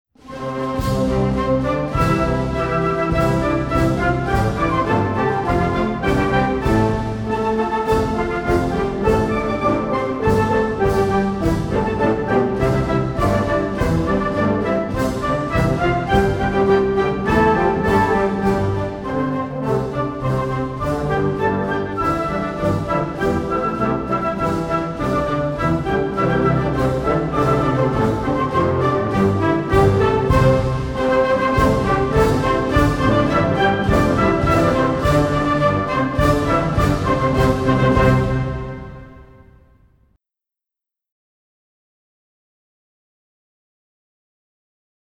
เพลงมหาฤกษ์ (วงโยวาทิต)